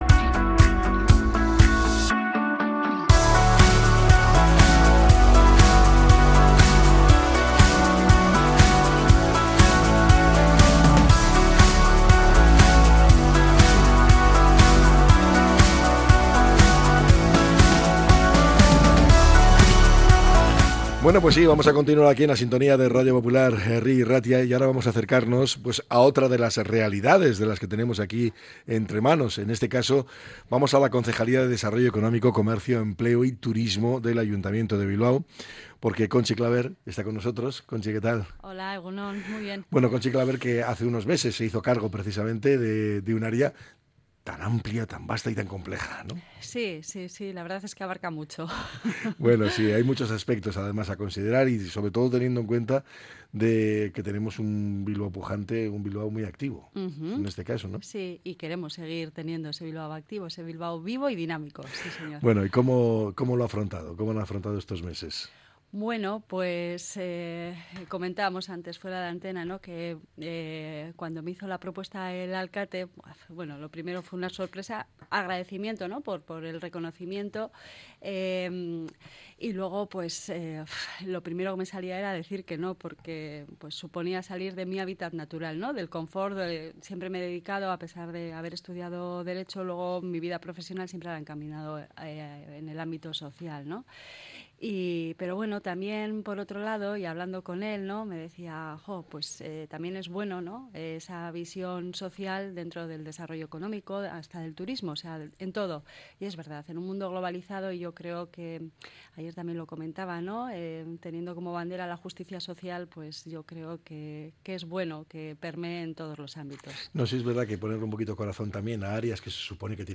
La concejala de Desarrollo Económico, Comercio, Empleo y Turismo de Bilbao analiza los desafíos de la ciudad